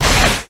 snd_missile_nuke.ogg